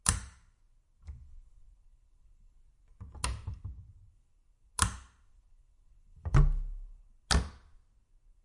随机 "镶嵌玻璃门的木质橱柜开合点击率1
描述：门木柜与镶嵌玻璃打开close clicks1.flac
Tag: 点击 打开 关闭 木材 玻璃 橱柜 镶嵌